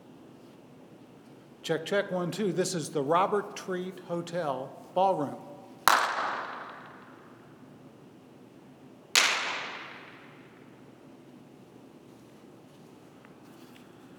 Here is what the larger space sounds like. File 1 is a recording made on the iPhone using the FiRe app. Note my inability to produce a consistent hand clap. The noise floor of this space was not ideal, but workable.
Audio File 1 – Hand-clap recorded in the larger space.
handclap_large-space.wav